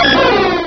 sovereignx/sound/direct_sound_samples/cries/celebi.aif at master